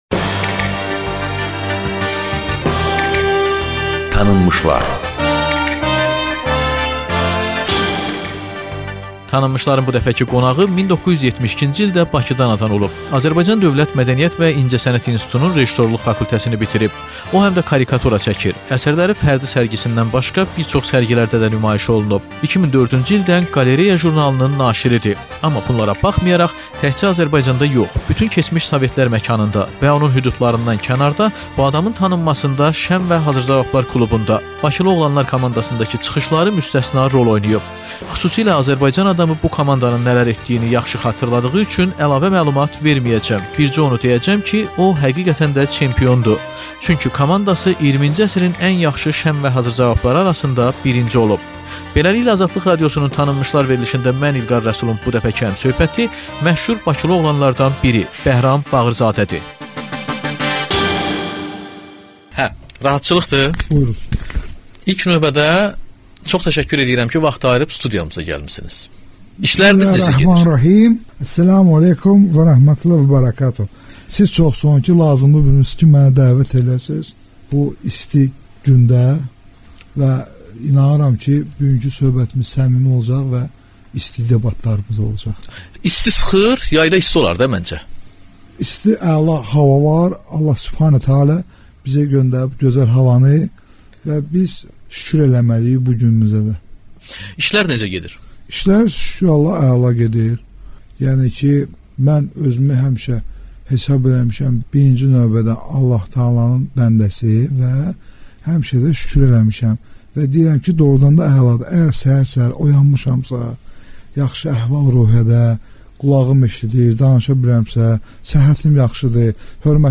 "Tanınmışlar" proqramında məşhur "Bakılı oğlan"la söhbət